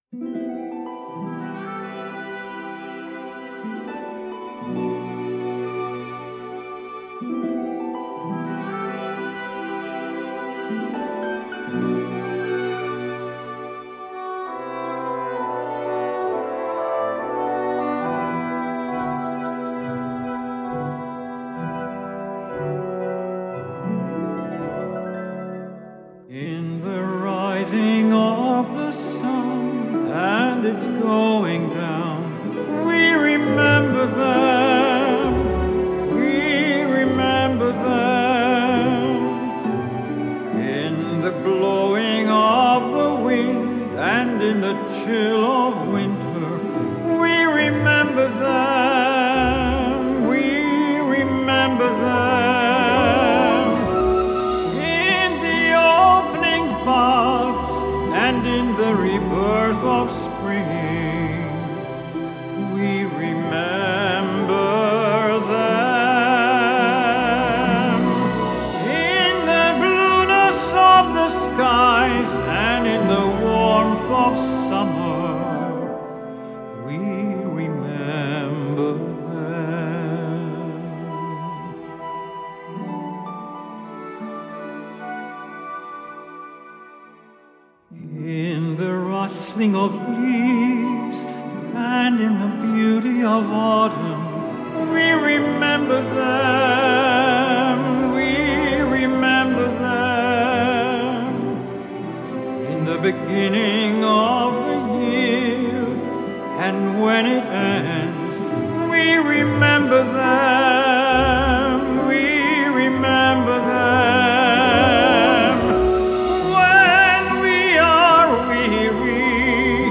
Such music is an essential element of the worship services at Temple Emanu-El of Palm Beach – both in terms of instruments and voice.
All of the music here was recorded live during services, so what you will hear in the recordings is what you will experience in person in our Sanctuary.